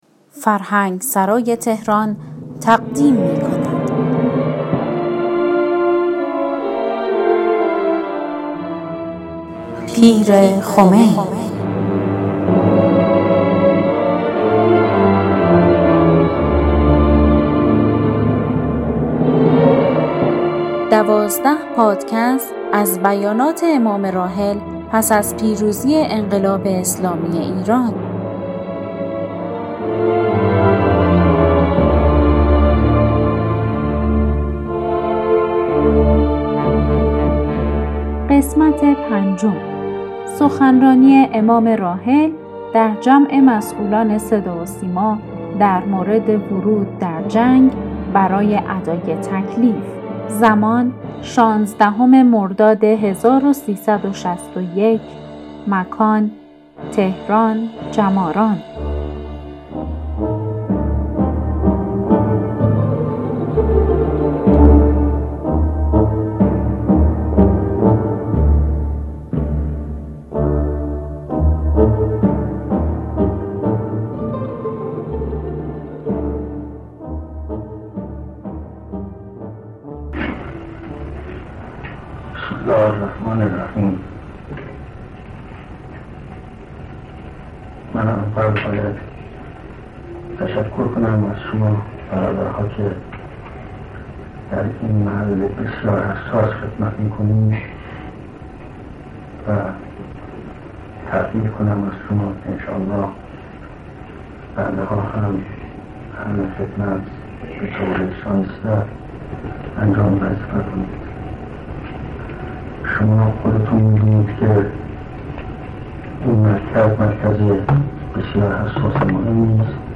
در ادامه سخنان امام (ره) را در جمع مسئولان صدا و سیما که پیرامون حضور در جنگ برای ادای تکلیف در مرداد ماه سال 1361 در جماران تهران ایراد کردند، می‌شنویم.